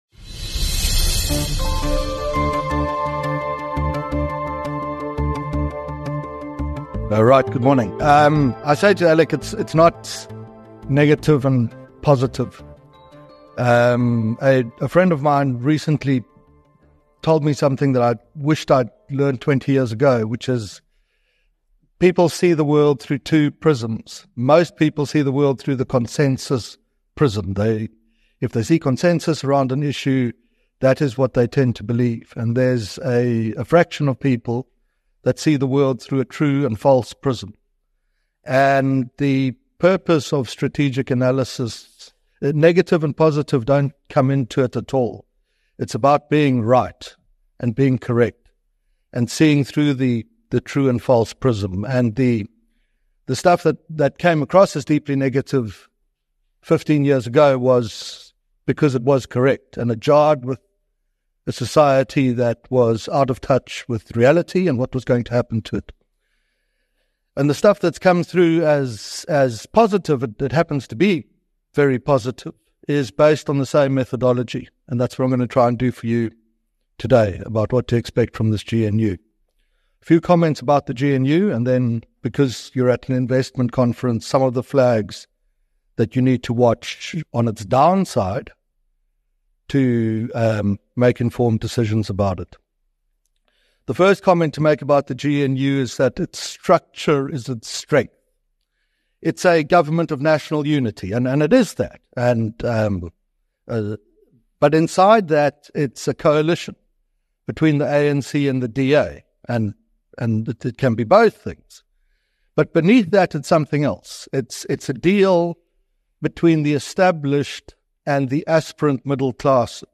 In a compelling keynote session at the BizNews Investment Conference BNIC#1